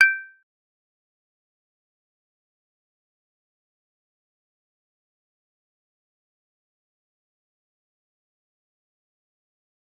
G_Kalimba-G7-mf.wav